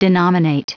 Prononciation du mot denominate en anglais (fichier audio)
Prononciation du mot : denominate